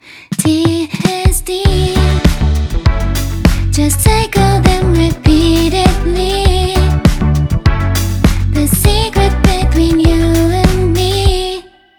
さてそれでは、ここから怒涛の視聴タイムです!! 4コードのループで、TDSの違いを感じてみましょう。今回は単一のメロディを使い回して、さまざまなコード進行をあてていくことにします。
もちろん機能のサイクルを順行/逆行でぐるぐる回るだけではなく、TDTSTSTDのように方向を変えて行ったり来たりすることも可能です。